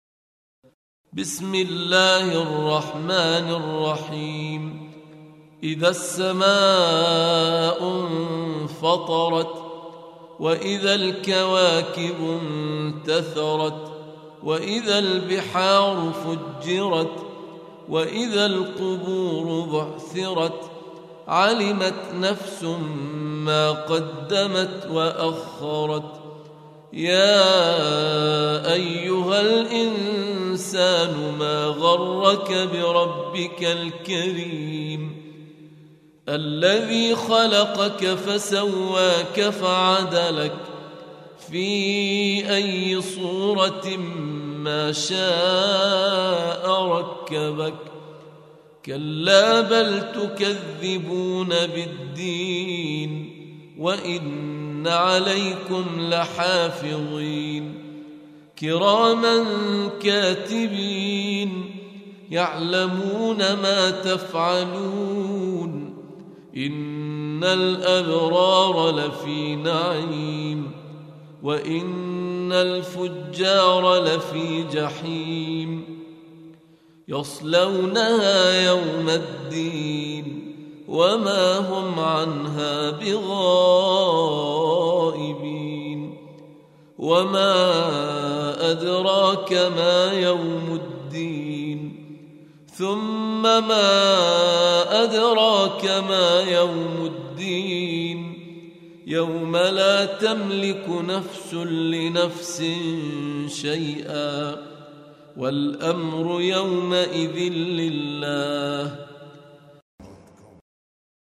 82. Surah Al-Infit�r سورة الإنفطار Audio Quran Tarteel Recitation
Surah Sequence تتابع السورة Download Surah حمّل السورة Reciting Murattalah Audio for 82.